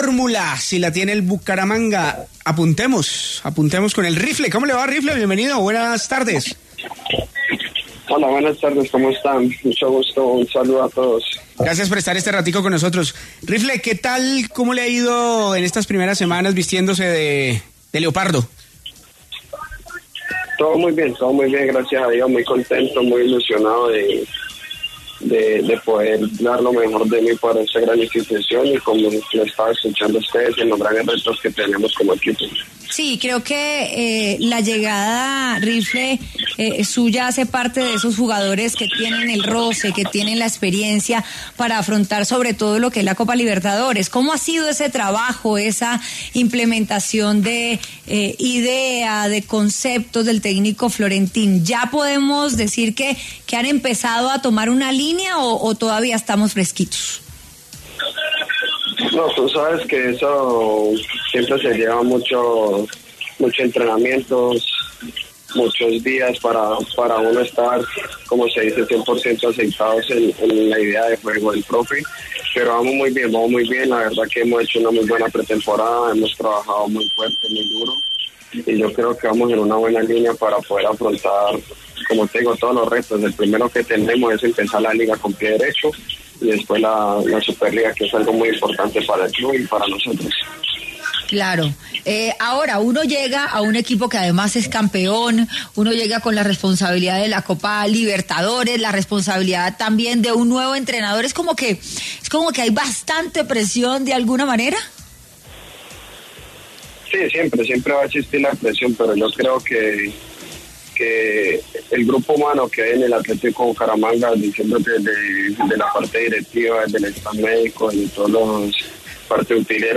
En charla con El VBAR Caracol, esto dijo el mediocampista sobre su llegada al cuadro santandereano: “Muy contento e ilusionado con el equipo. Quiero dar lo mejor de mí para esta gran institución y poder así lograr todos los objetivos que tenemos como club”.